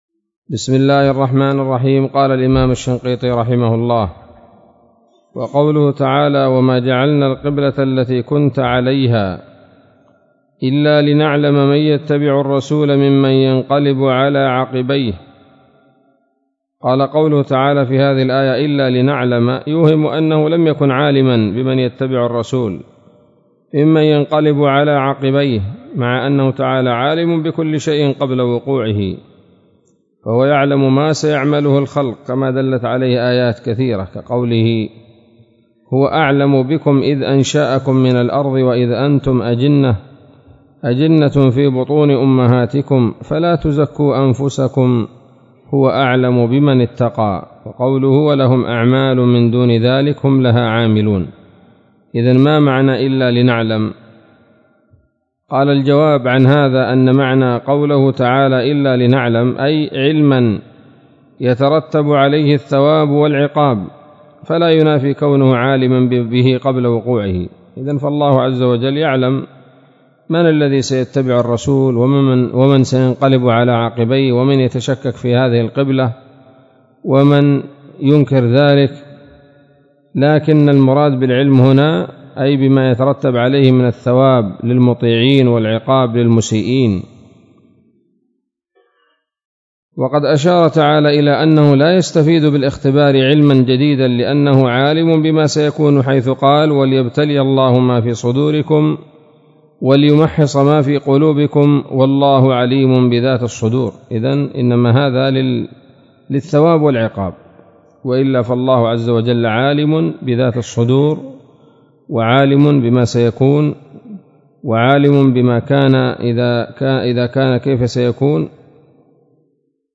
الدرس الرابع عشر من دفع إيهام الاضطراب عن آيات الكتاب